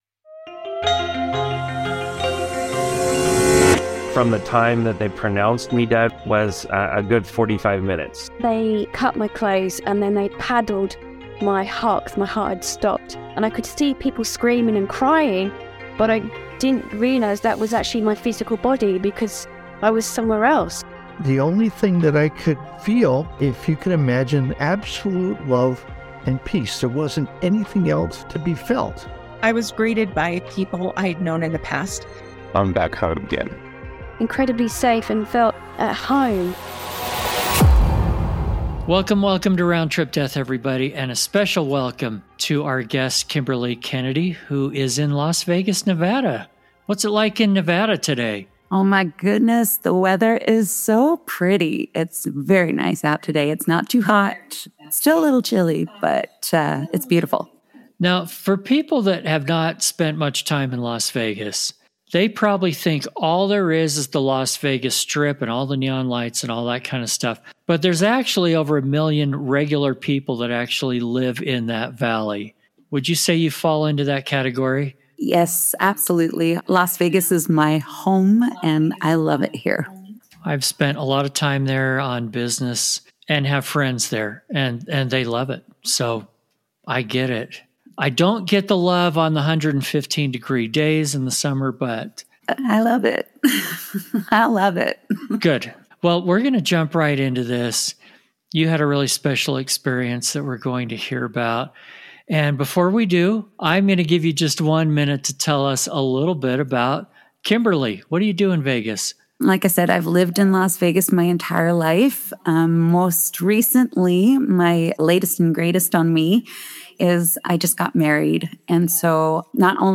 Round Trip Death podcast features discussions with people who have actually died, visited the other side, and returned to talk about it.